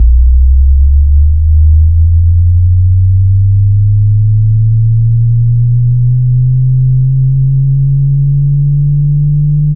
Rising Sub 65-12.wav